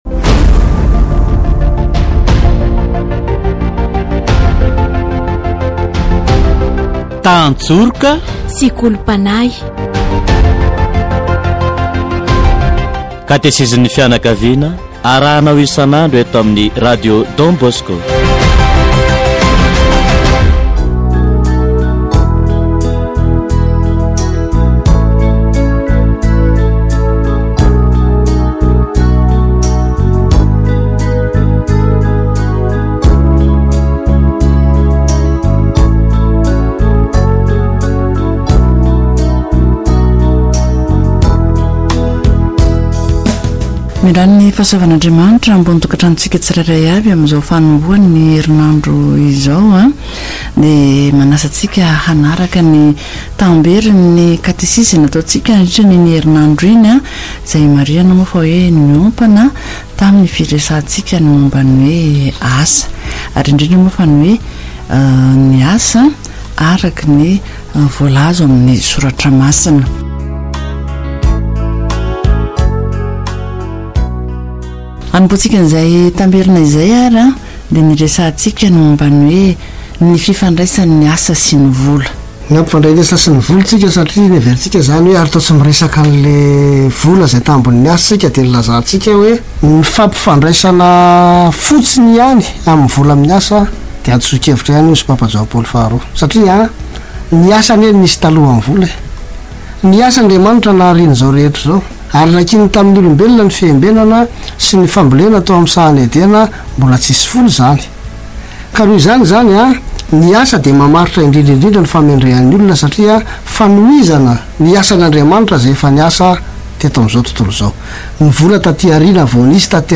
Category: Deepening faith